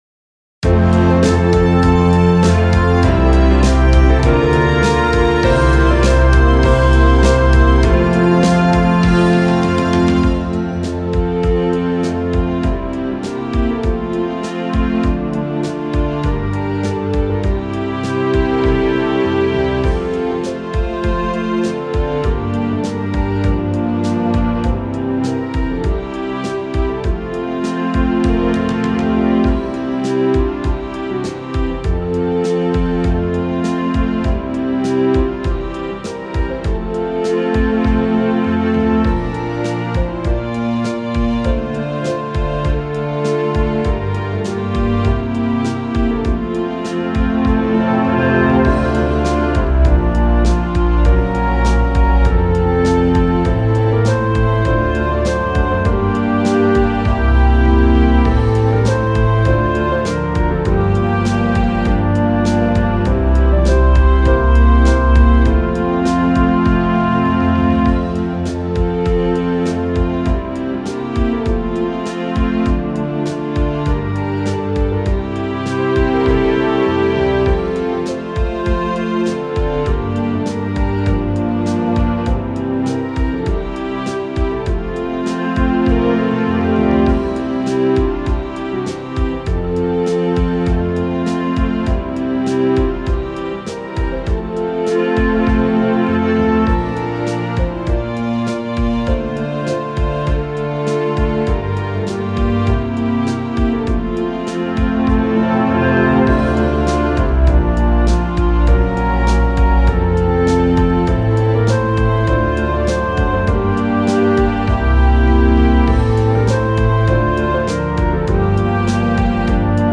효정중학교 교가 음원 :울산교육디지털박물관